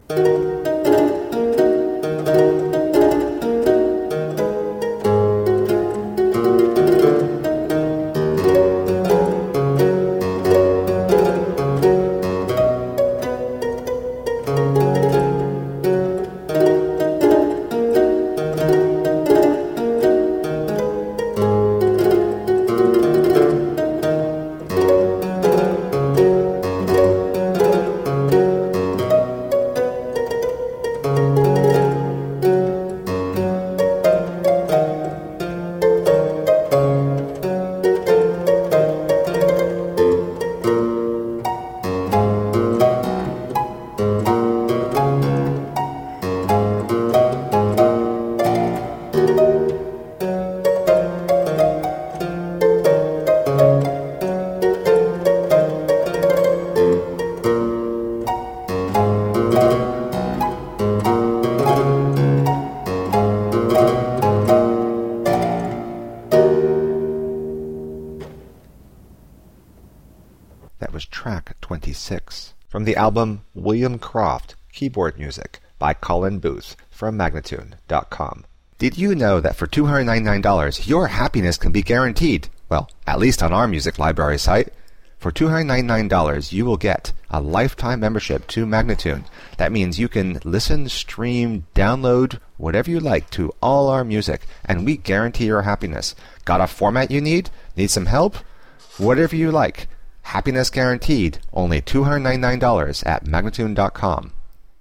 Solo harpsichord music.
Classical, Baroque, Instrumental
Harpsichord